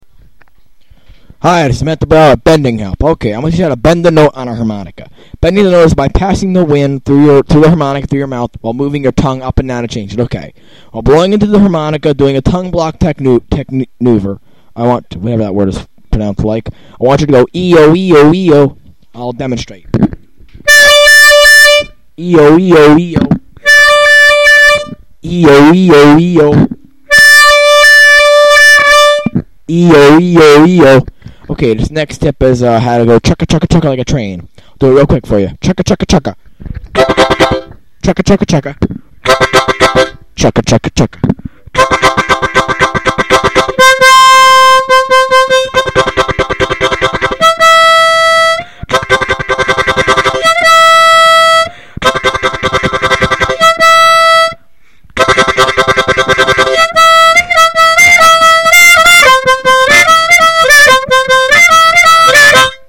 Harp Tutorials
Demo Clip